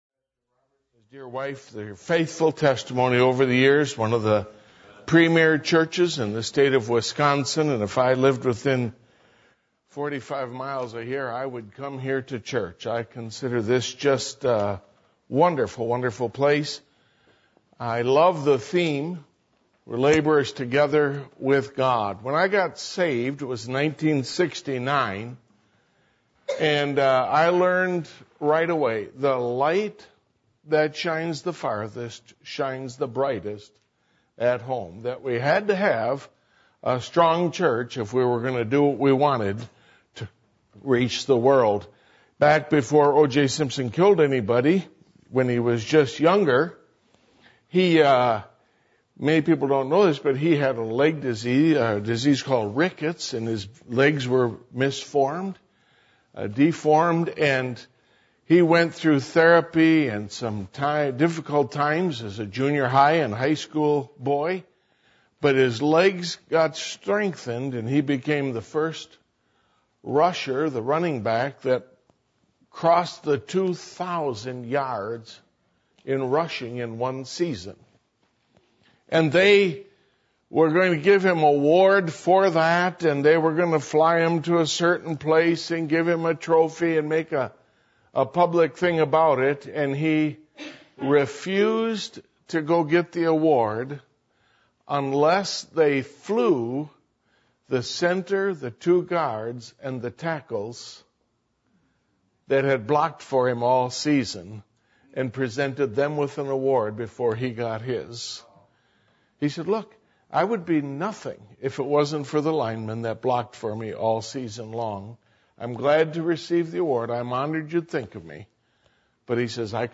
Passage: Philippians 4:10-19 Service Type: Missions Conference